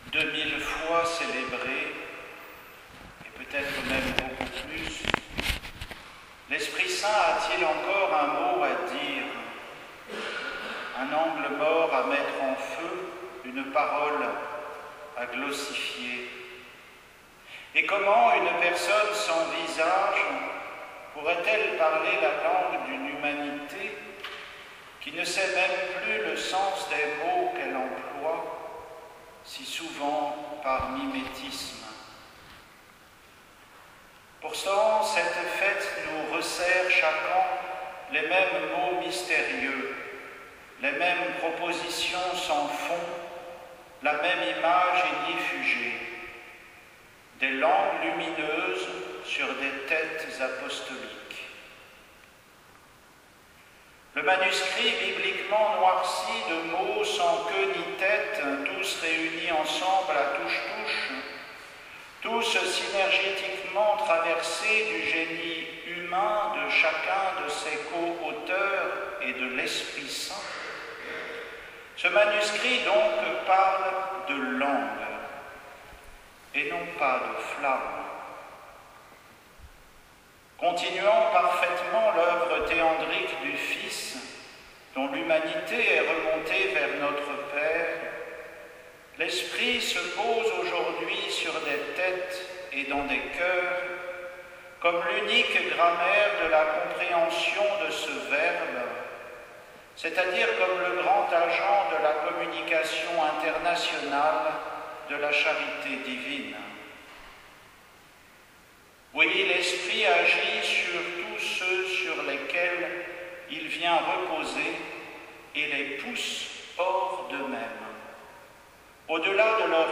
Homélie du dimanche 5 juin